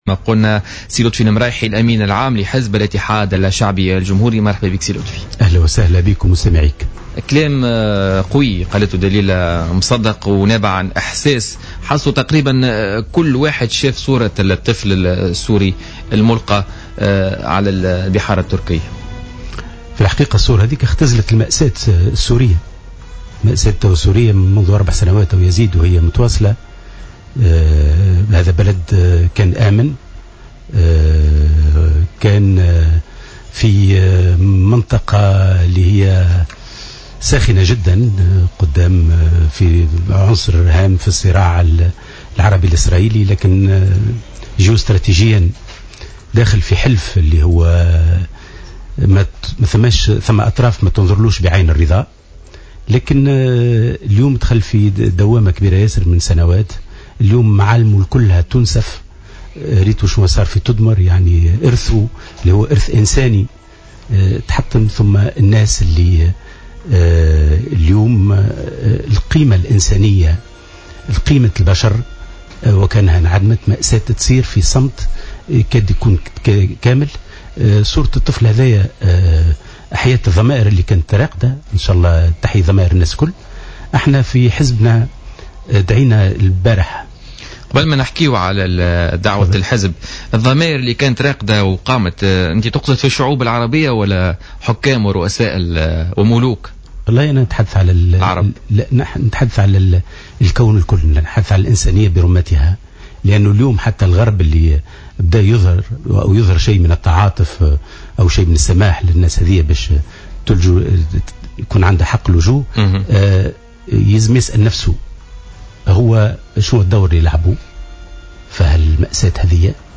أكد أمين عام حزب الإتحاد الشعبي الجمهوري لطفي المرايحي ضيف "بوليتيكا" اليوم الخميس 3 ىسبتمبر 2015 أن صورة الطفل الملفى على الشواطئ التركية اختزلت المأساة السورية مشيرا الى أن الغرب بدأ بإظهار نوع من التعاطف من خلال سماح عدة بلدان بلجوء سوريين إليها.